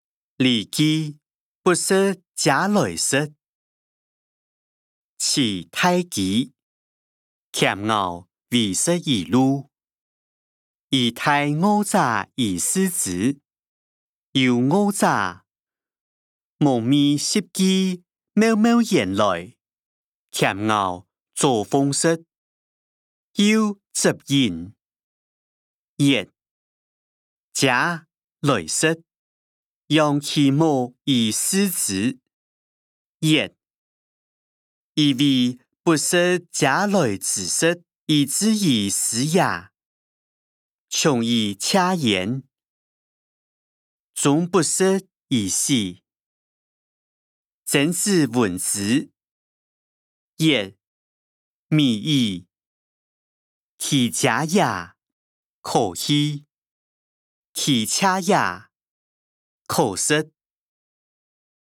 經學、論孟-不食嗟來之食音檔(四縣腔)